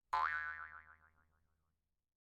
Boing
boing cartoon coil film jaw-harp jews-harp recoil release sound effect free sound royalty free Movies & TV